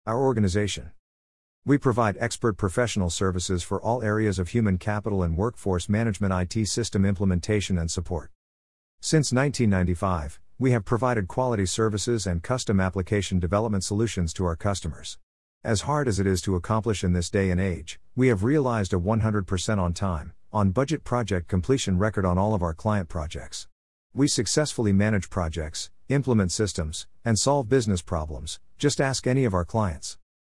easytts_audio_Our-Organization1-1.mp3